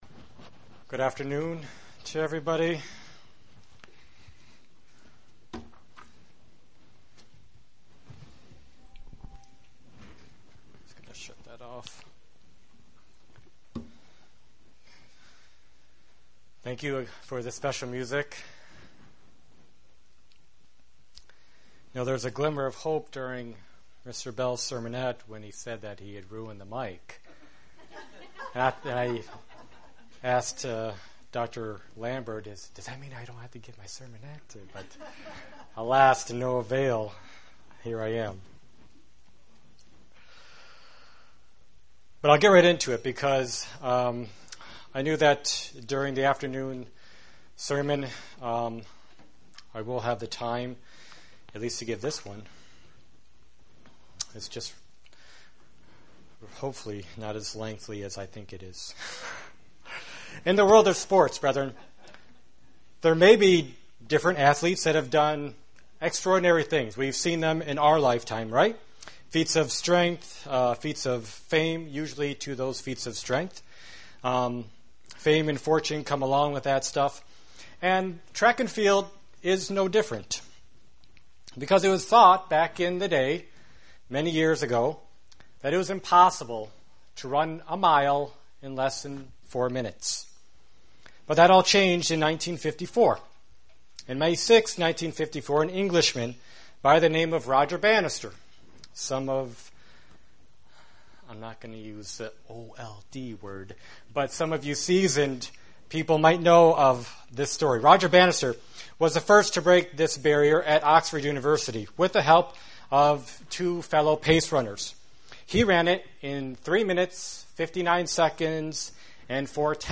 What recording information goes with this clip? Given in Buffalo, NY Elmira, NY